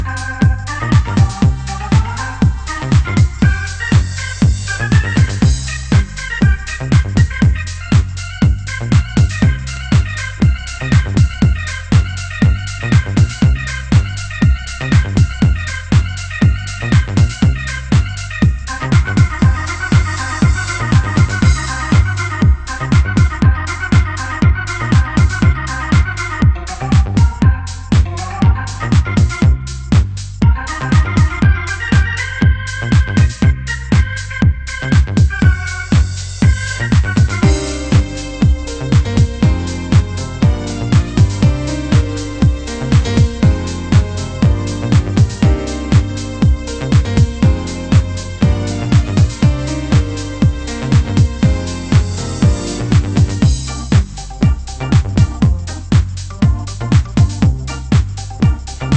HOUSE MUSIC
(Vocal)
(Instrumental)